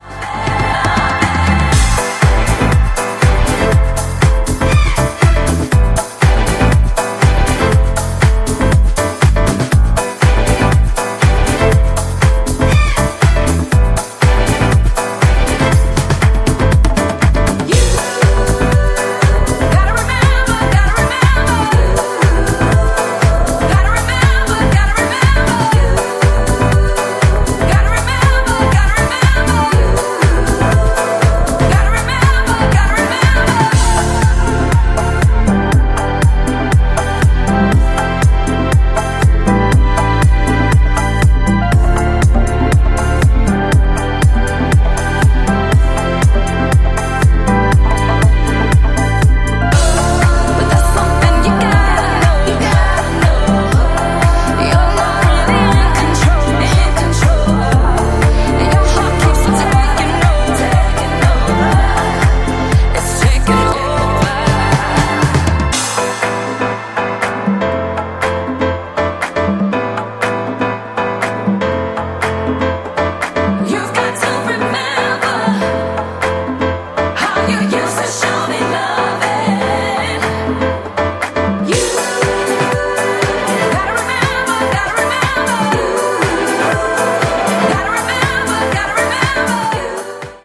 ジャンル(スタイル) DISCO HOUSE / SOULFUL HOUSE